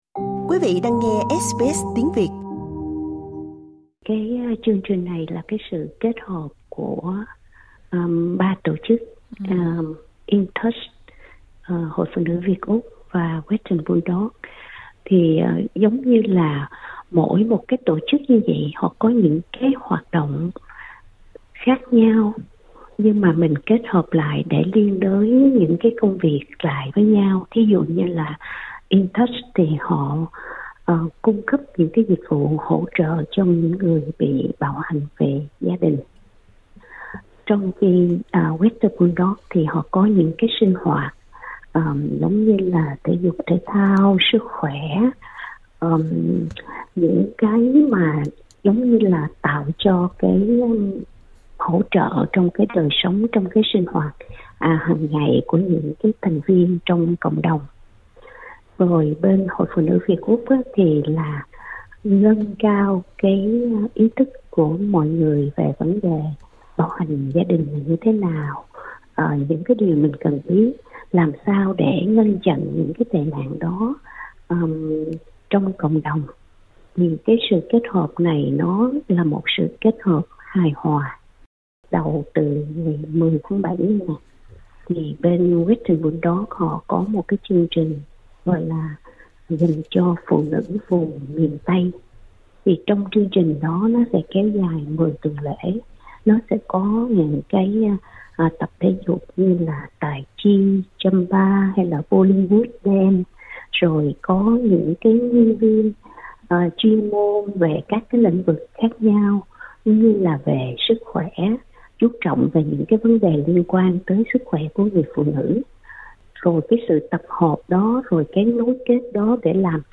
SBS Việt ngữ